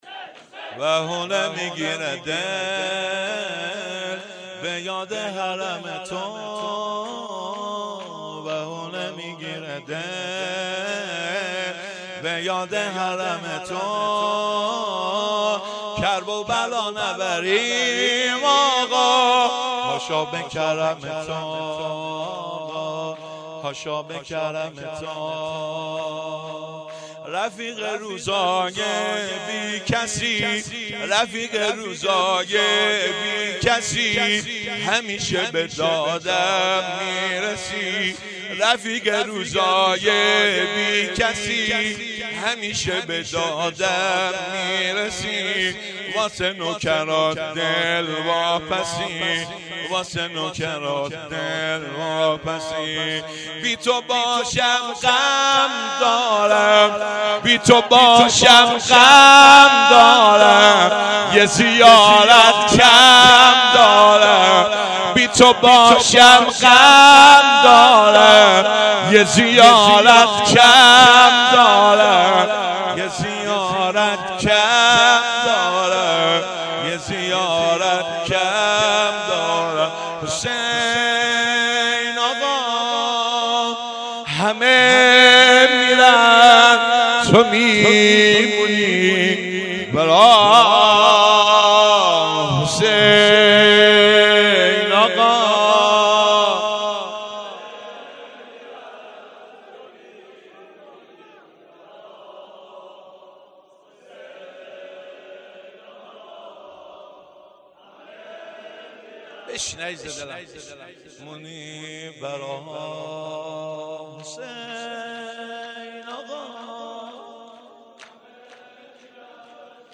مراسم عزاداری محرم ۱۴۰۲